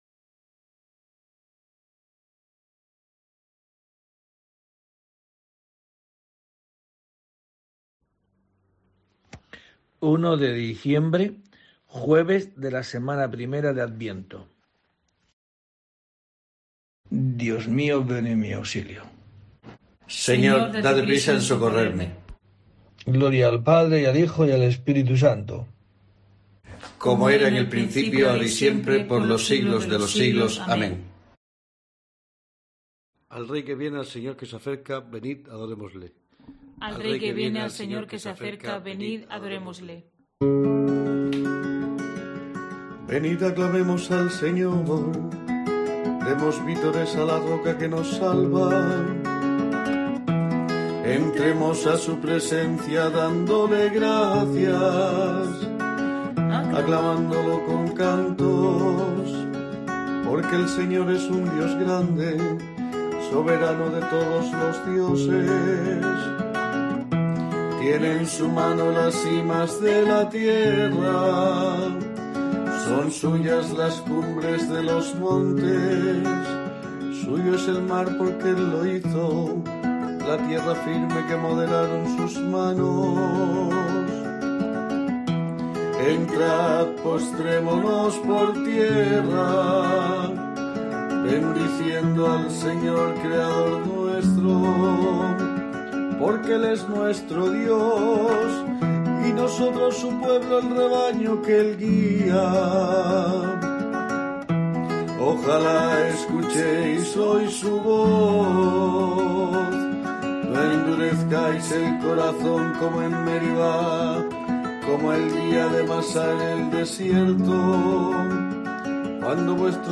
1 de diciembre: COPE te trae el rezo diario de los Laudes para acompañarte